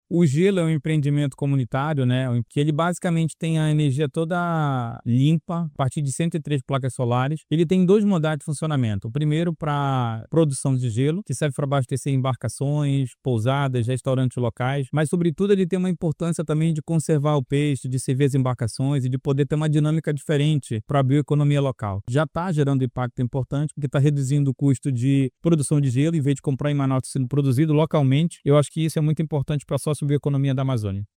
SONORAHOMEM-FAS.mp3